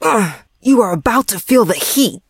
maisie_hurt_vo_01.ogg